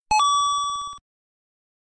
PLAY COIN wrong warp
coin-noise.mp3